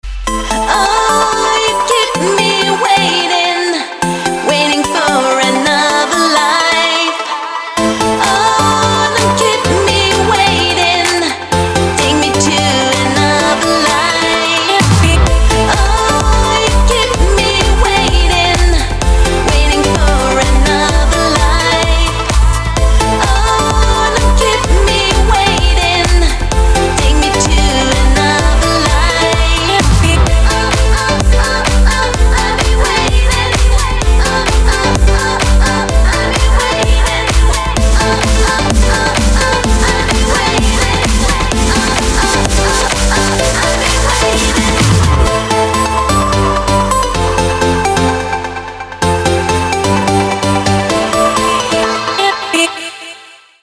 громкие
женский вокал
dance
Electronic
электронная музыка
клавишные
club
house